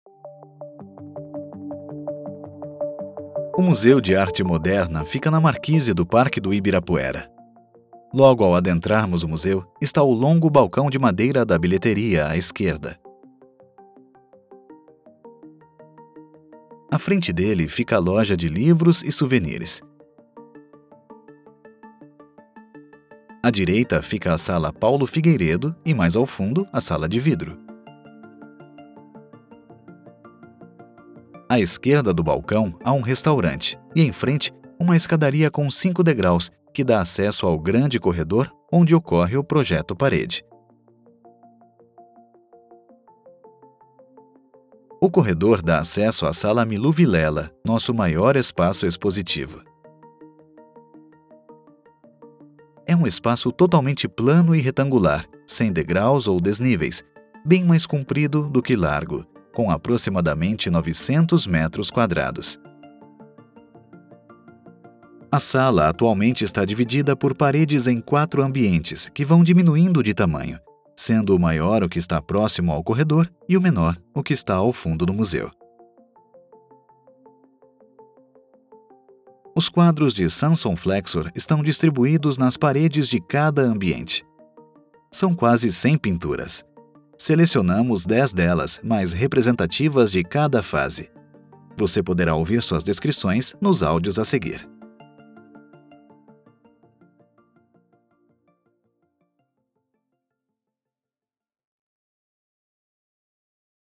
02-descricao-do-espaco-expositivo-2.mp3